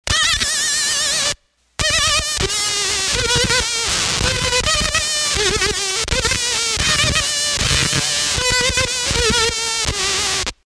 Citation : pfruuuuuuitttt fraaaaat skuiiiiiizzzzz abelelelelele
atoner-buzz.mp3